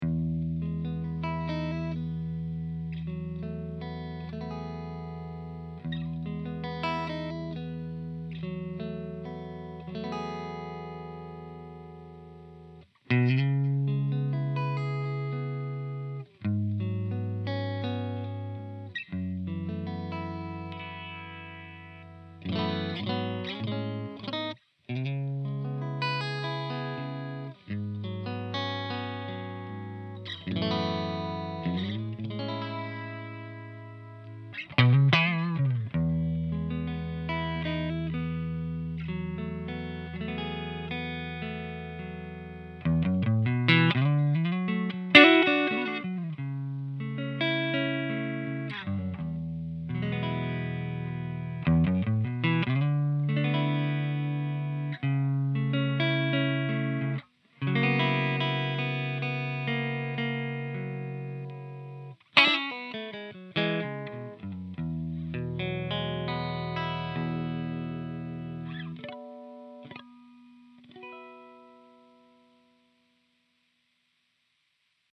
Clean riff 2